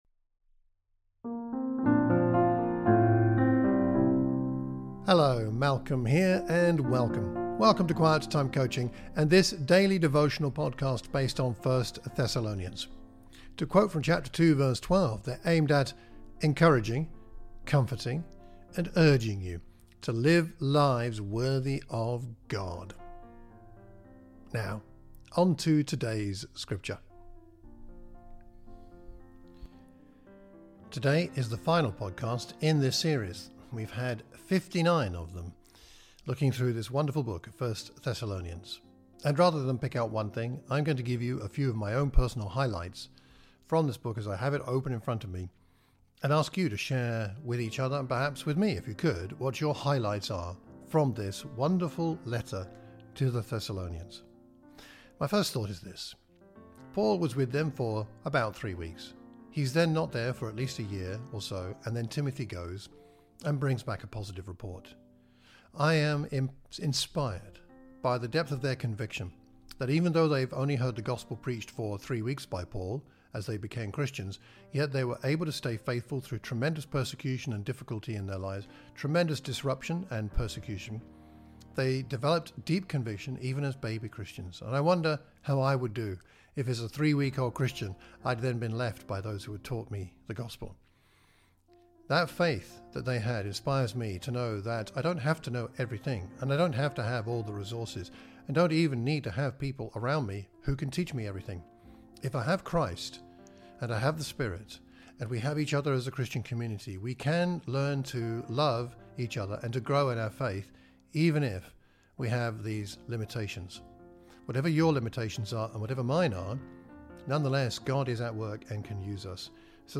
You have found a daily devotional podcast on 1 Thessalonians. These recordings accompany the teaching and preaching series for the Thames Valley and Watford churches of Christ.